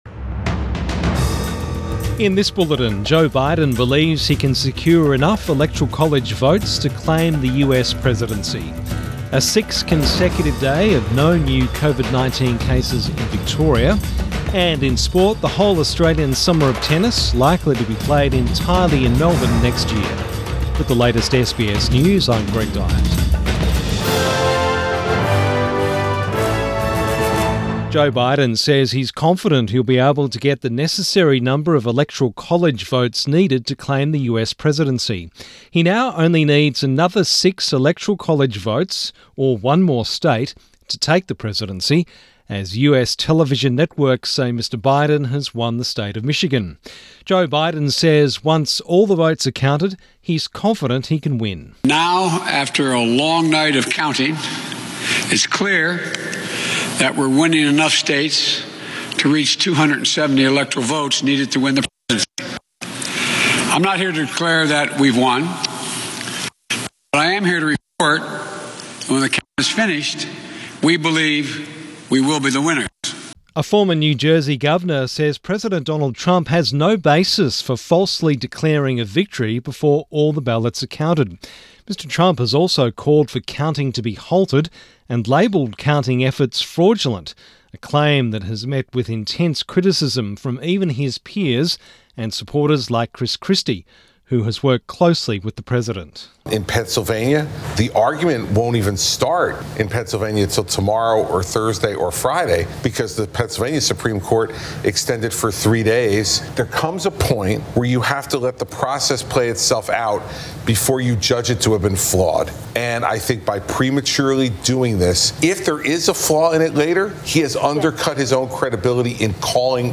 Midday bulletin 5 November 2020